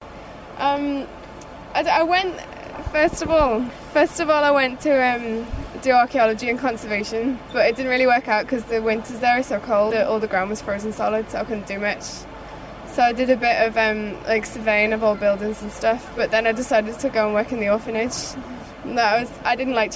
Birm Plym Female student monologue EJDD
Play Sample: Birm-Plym-Female-student-monologue-EJDD.mp3